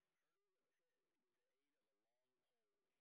sp09_train_snr20.wav